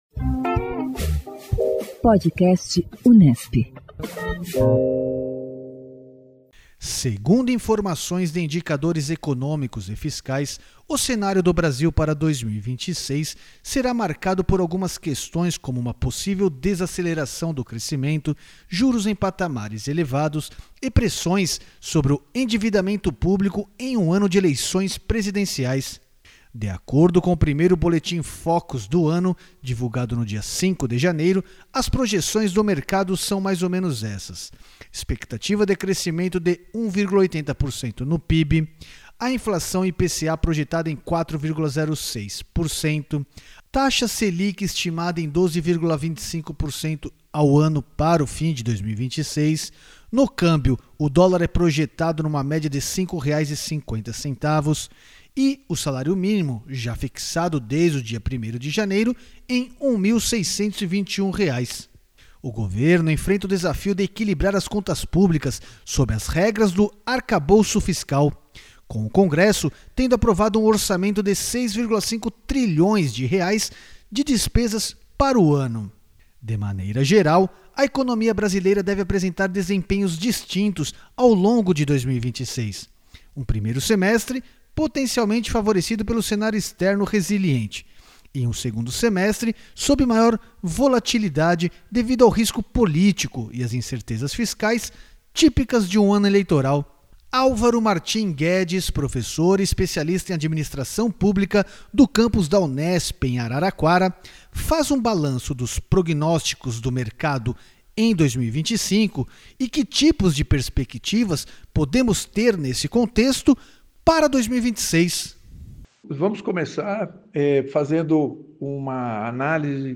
O Podcast Unesp / Assessoria de Comunicação e Imprensa da Reitoria da Unesp traz entrevistas com professores, pesquisadores e alunos sobre pautas cotidianas da mídia brasileira, internacional e informações geradas na Universidade.